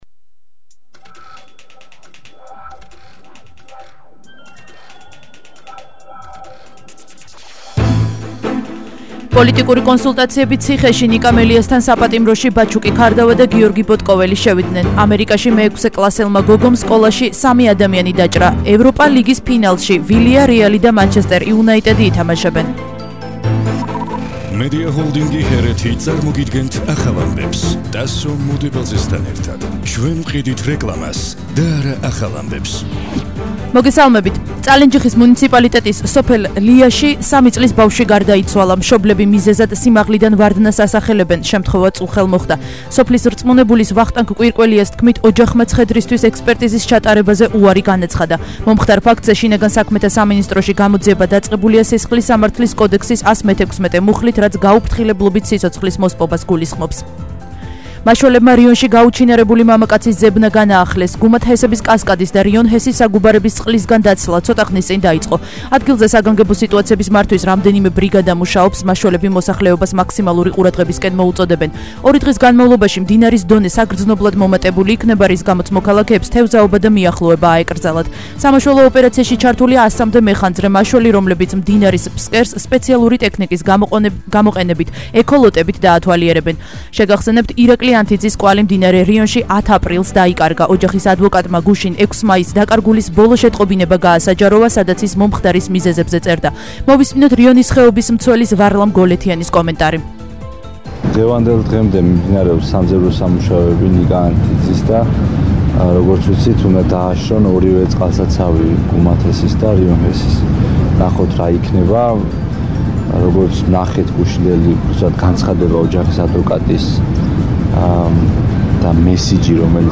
ახალი ამბები 13:00 საათზე –07/05/21 – HeretiFM